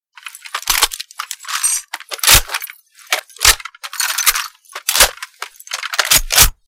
saw_reload.mp3